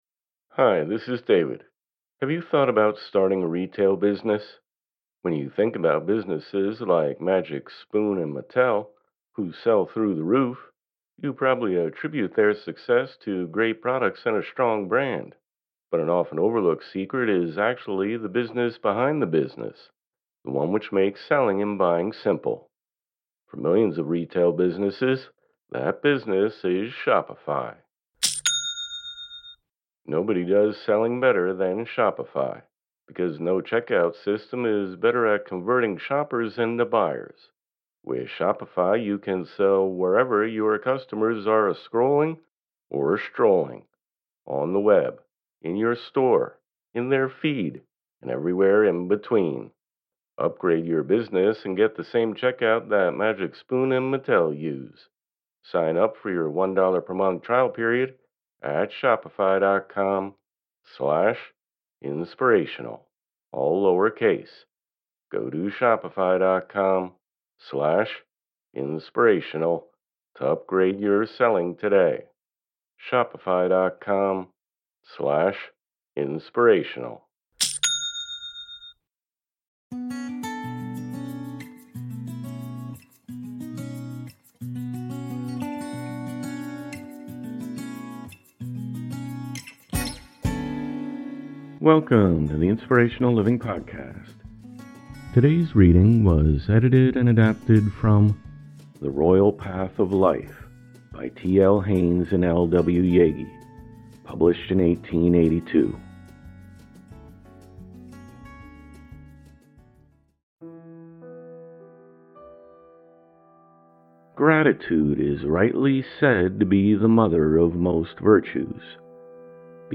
Look no further than the most motivational self-help authors of the past. Inspiring readings from James Allen, Napoleon Hill, Hellen Keller, Booker T. Washington, Khalil Gibran, Marcus Aurelius, and more.